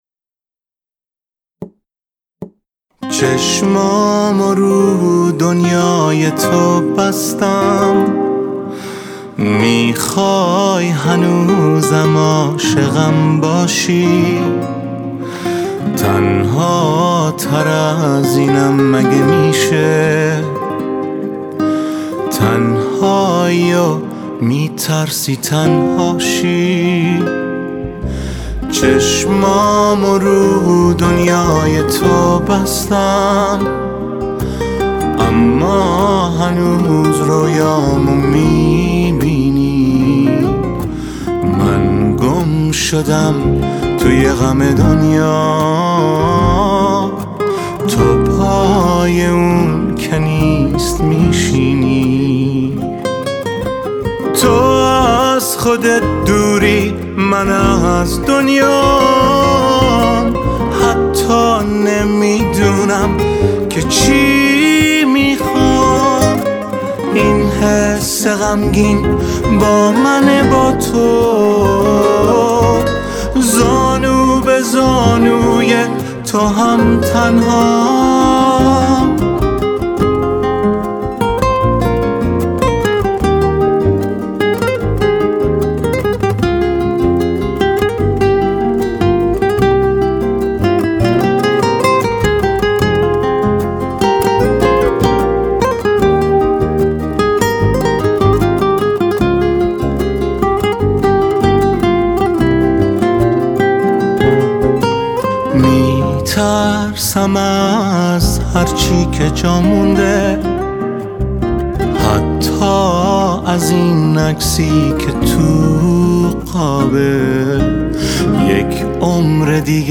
Genre: Pop
Guitar